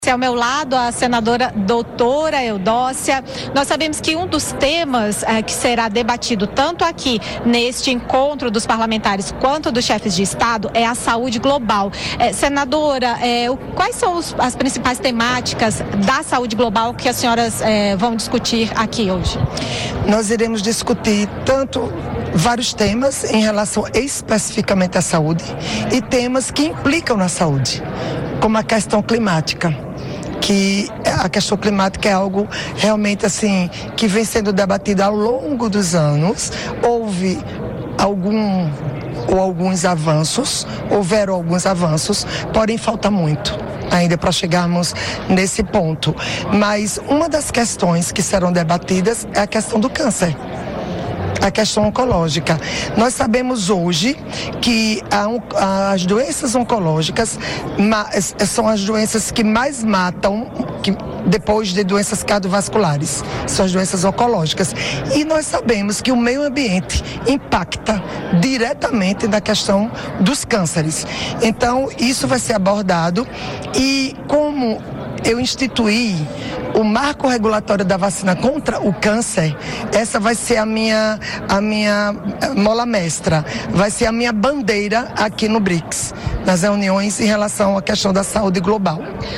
Durante entrevista à Rádio Senado, a senadora Dra. Eudócia (PL-AL) destacou que pretende abordar nas reuniões do Fórum Parlamentar do BRICS o marco regulatório da vacina contra o câncer.